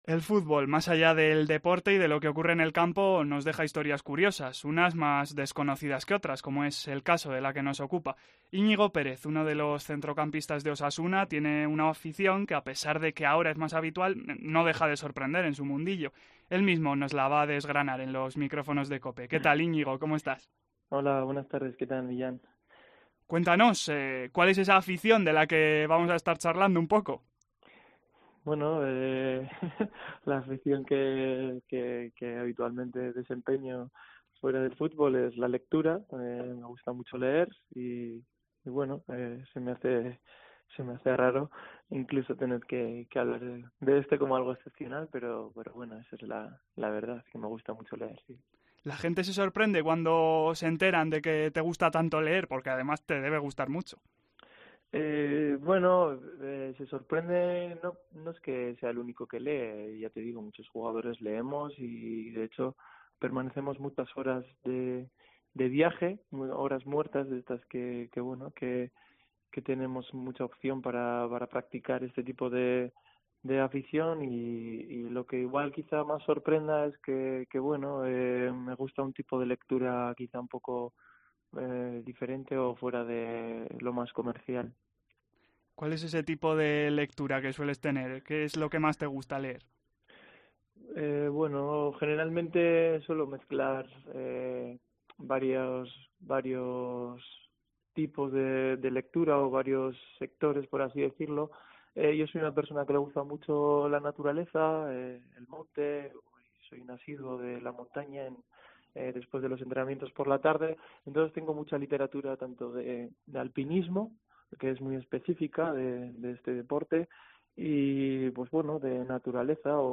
Al abordar una de sus grandes aficiones, Pérez demuestra ser un conversador excelso.